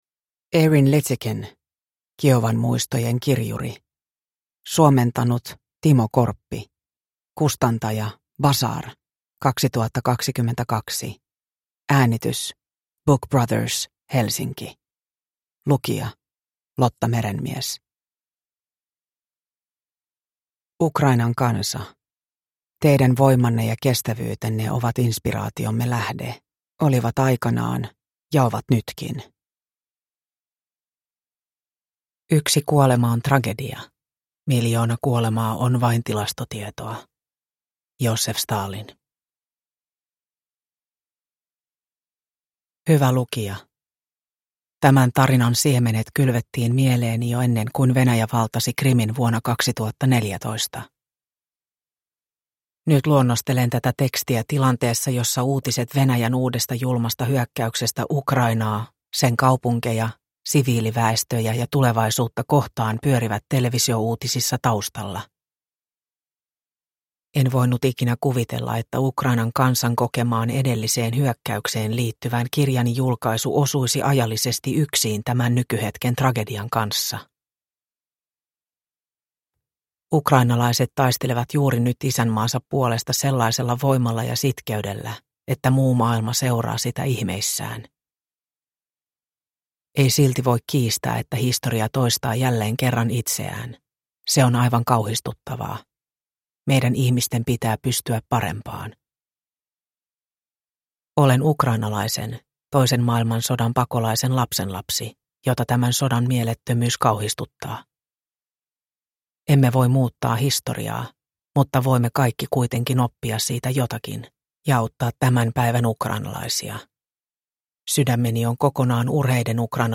Kiovan muistojen kirjuri – Ljudbok – Laddas ner